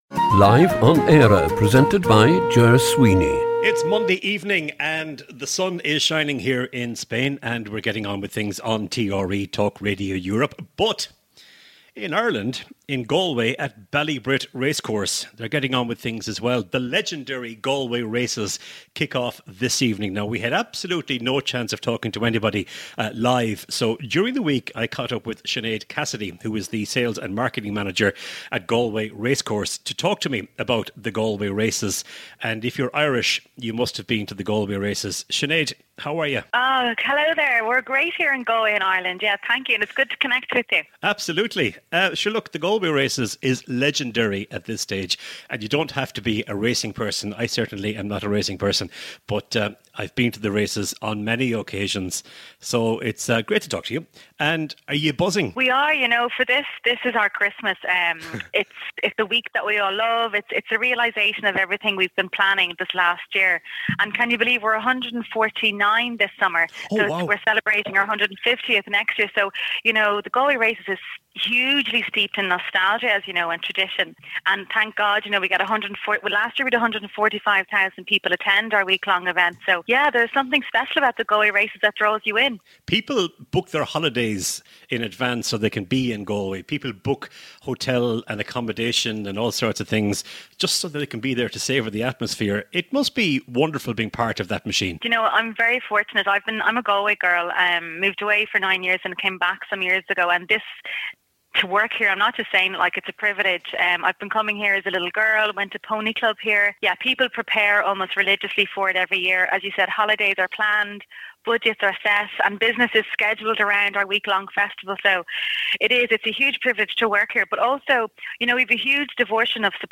with live guests and regular contributors from both countries.